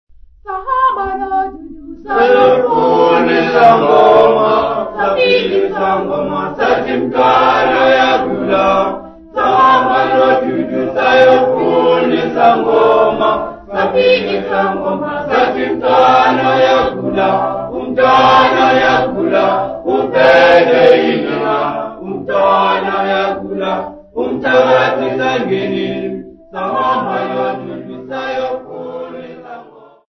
Folk music
Sacred music
Field recordings
Africa South Africa Pietersburg sa
sound recording-musical
Church music workshop recording, unaccompanied.